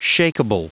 Prononciation du mot shakeable en anglais (fichier audio)
Prononciation du mot : shakeable